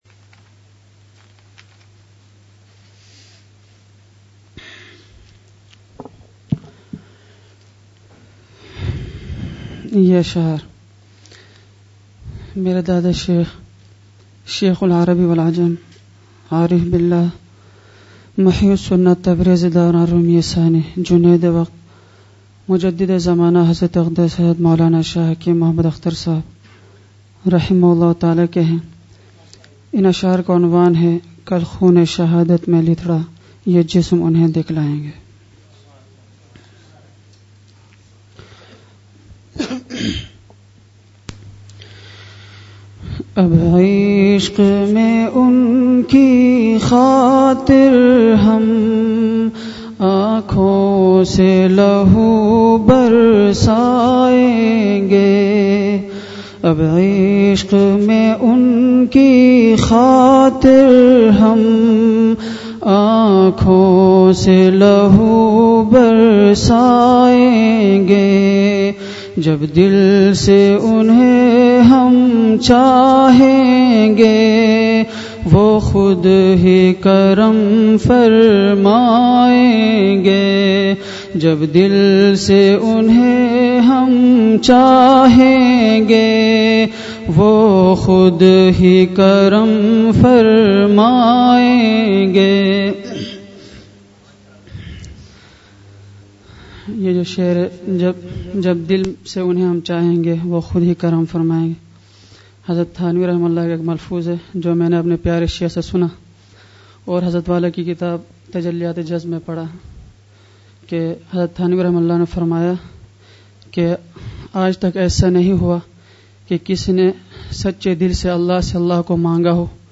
آج کی مجلس کا دورانیہ ۵۵منٹ رہا۔